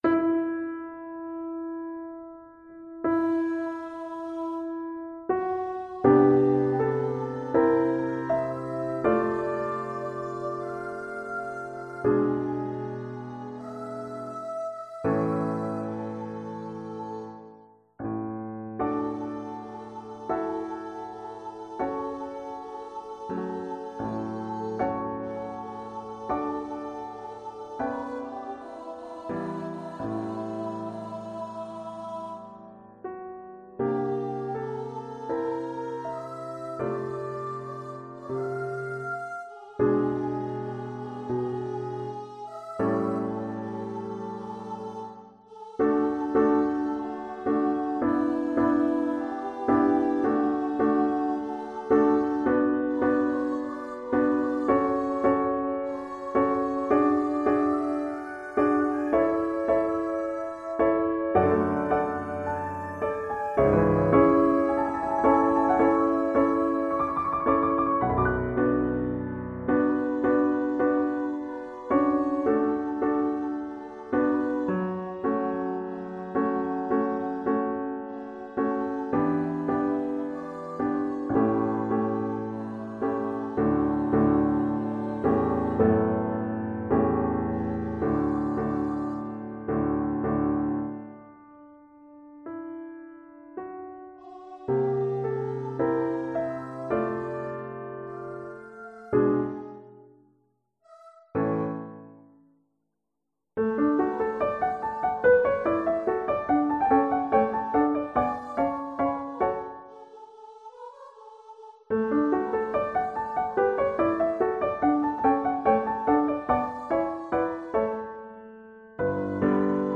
Play (or use space bar on your keyboard) Pause Music Playalong - Piano Accompaniment Playalong Band Accompaniment not yet available transpose reset tempo print settings full screen
Voice
D major (Sounding Pitch) (View more D major Music for Voice )
Andante lento = 40
Classical (View more Classical Voice Music)